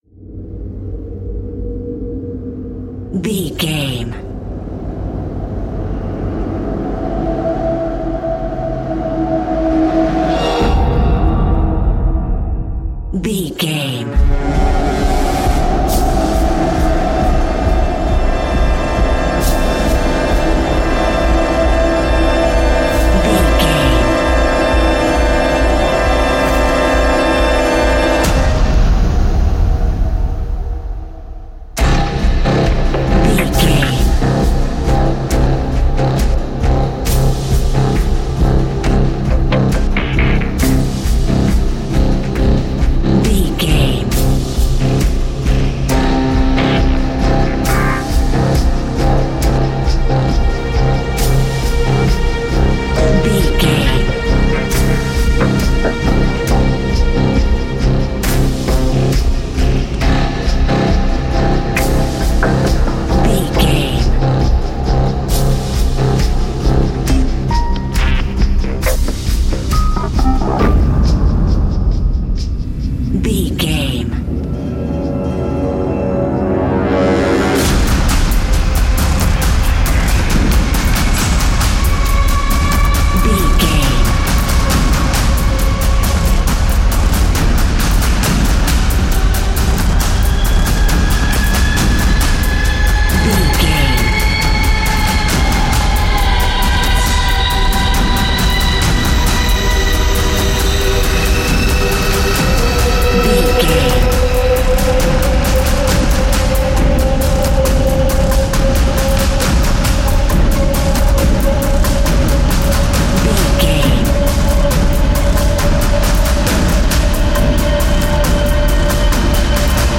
Fast paced
In-crescendo
Ionian/Major
C♭
industrial
dark ambient
EBM
drone
synths